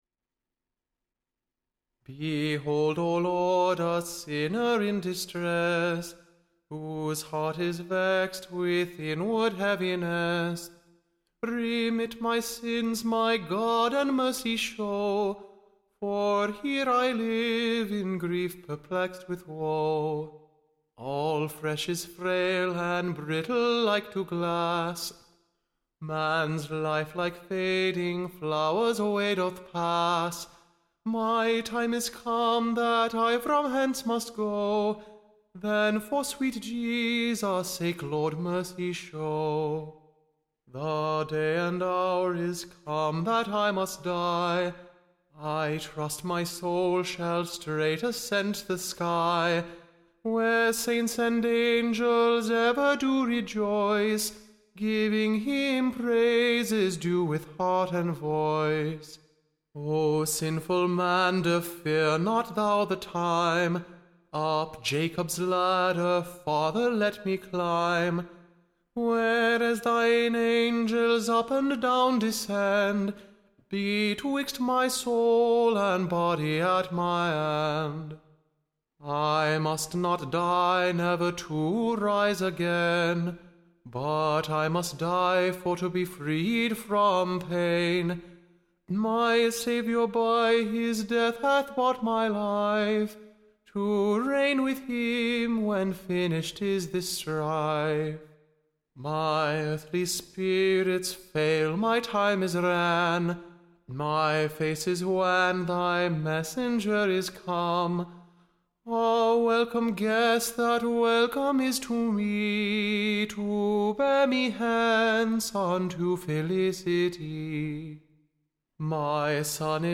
Tune Imprint To the Tune of, Fortune my Foe.